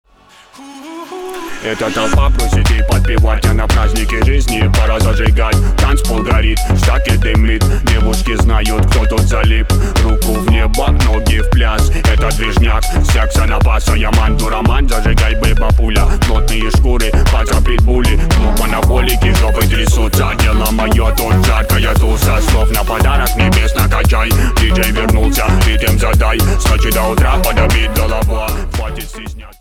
• Качество: 320, Stereo
ритмичные
мужской вокал
рэп
веселые
заводные
dance
club
быстрые
бас